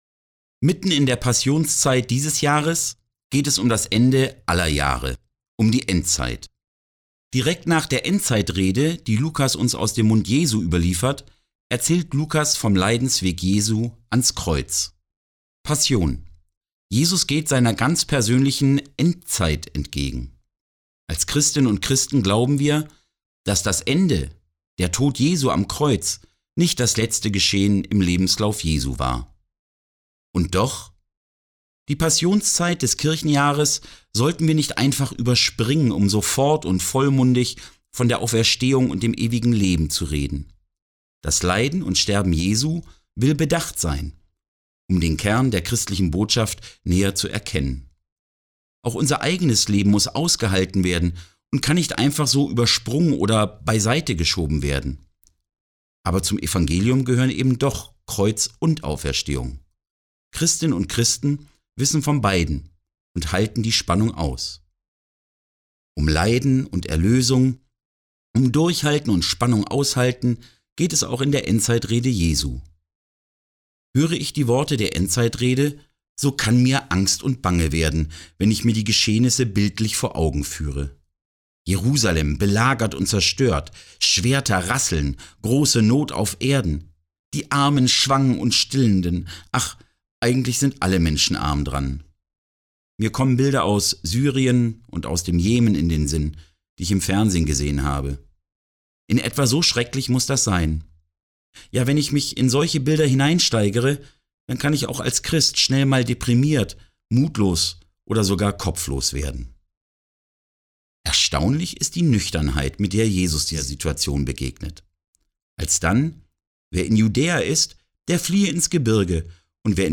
Ein Andacht in der Passionszeit zur Täglichen Bibellese am 18.3.2021/ Lukas 21,20-28
(diese Andacht wurde am 18.3.2021 im ERF-Radio ausgestrahlt)